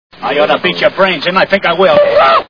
Three Stooges Movie Sound Bites